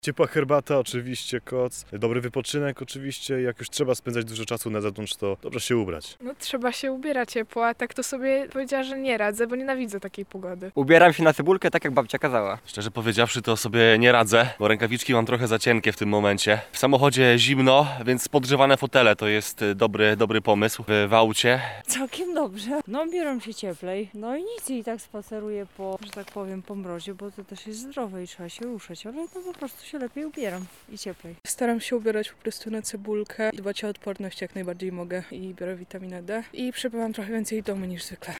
[SONDA] Mroźna aura w Lublinie. Jak mieszkańcy radzą sobie z zimą?
Zapytaliśmy mieszkańców miasta, w jaki sposób radzą sobie z niskimi temperaturami:
sonda